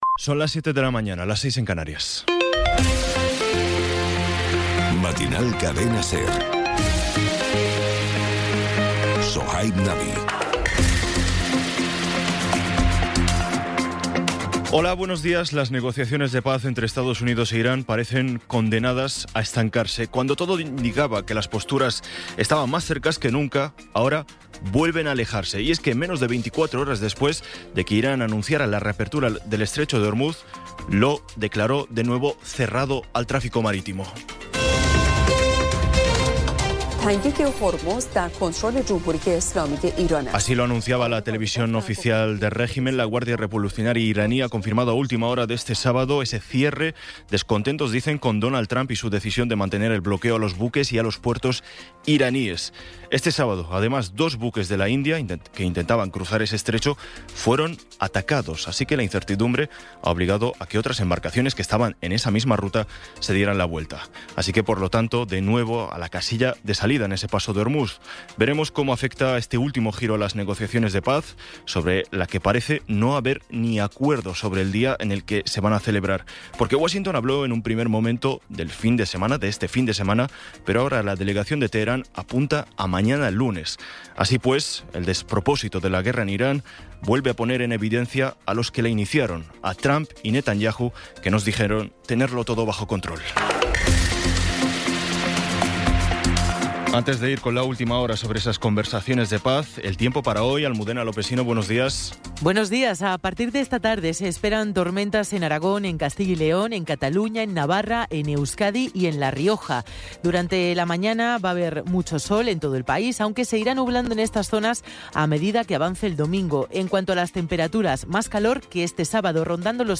Resumen informativo con las noticias más destacadas del 19 de abril de 2026 a las siete de la mañana.